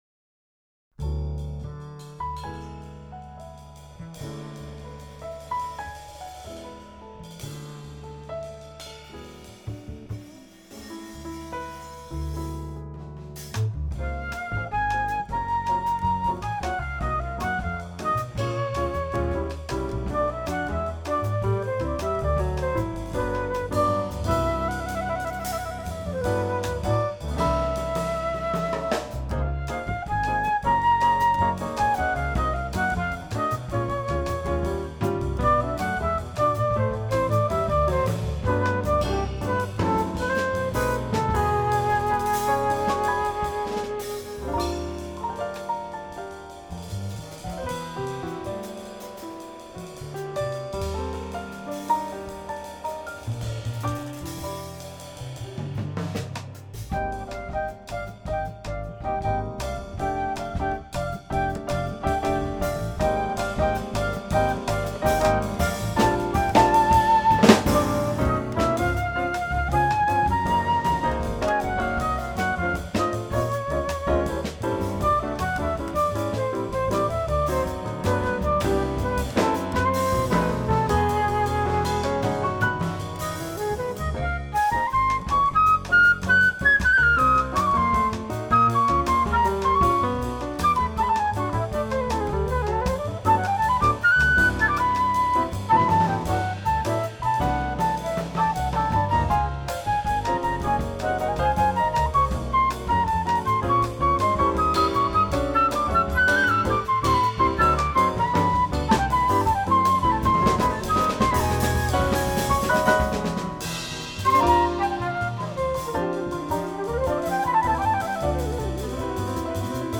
flute
piano
bass
drums